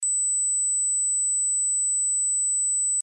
8khz- Everyone Can Hear